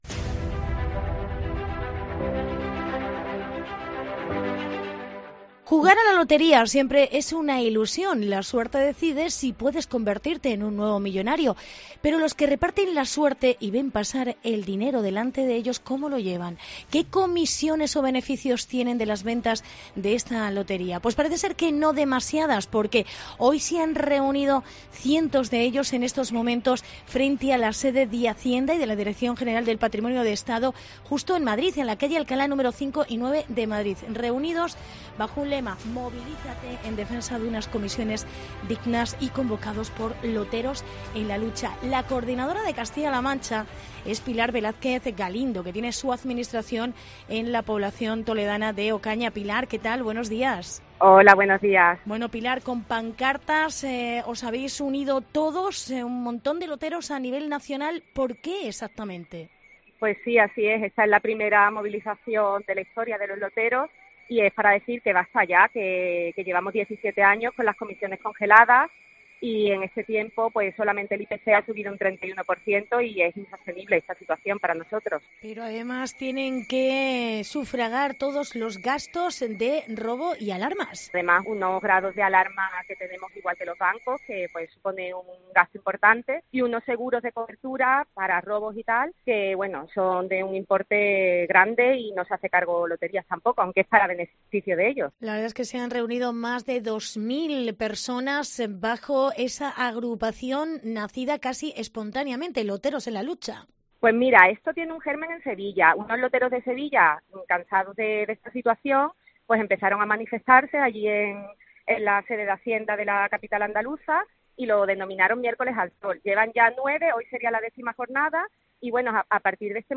Reportaje manifestación Loteros en la Lucha